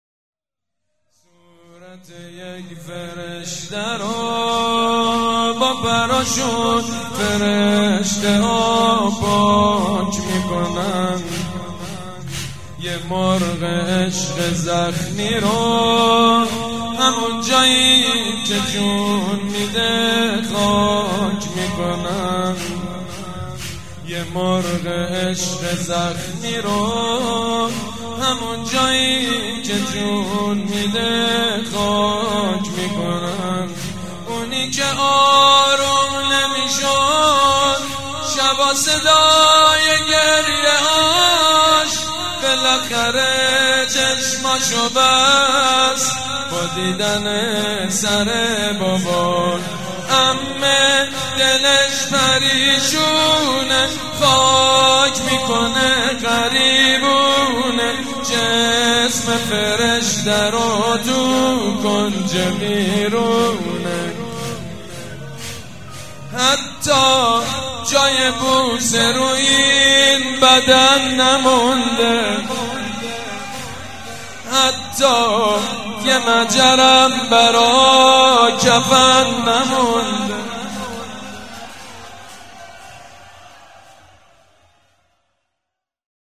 شب سوم محرم - به نام نامیِ حضرت رقیه(س)
زمینه سید مجید بنی فاطمه